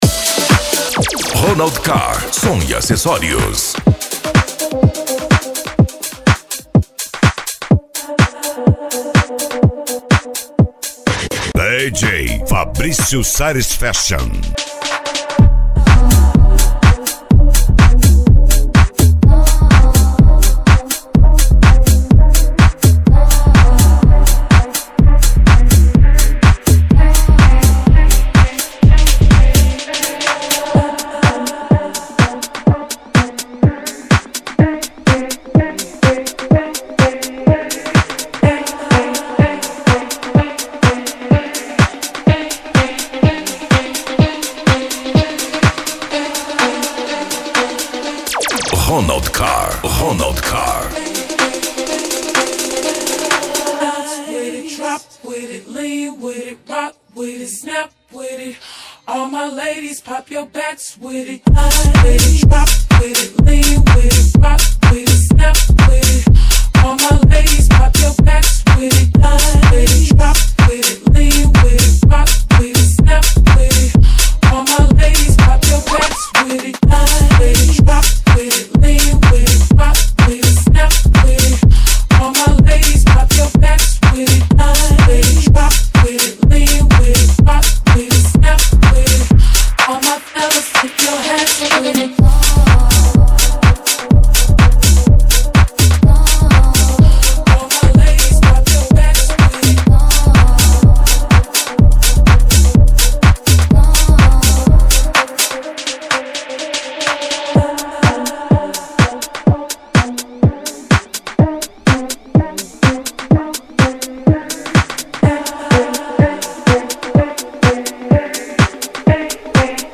Bass
Deep House
Euro Dance